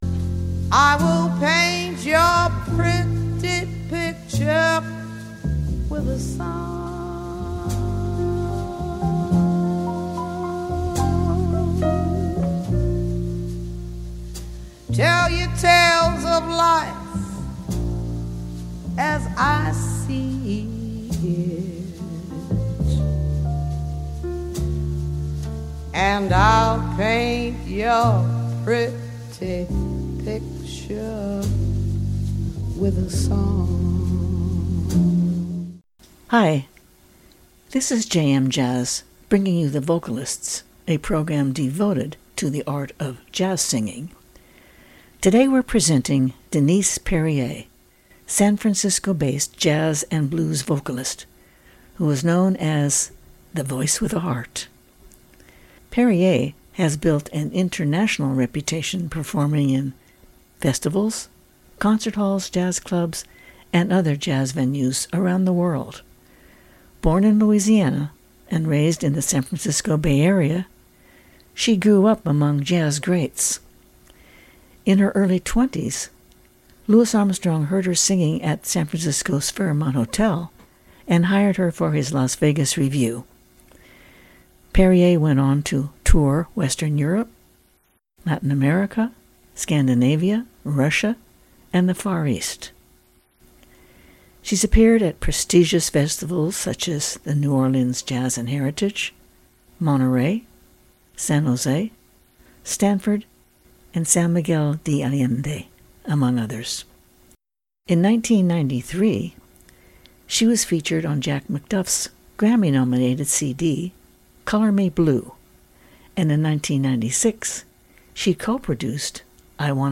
Listen to the excellent one-hour profile by jazz radio station WETF __________________________ TRIBUTE SHOWS VENUES & REVIEWS PHOTO ALBUM COMMENTS & QUOTES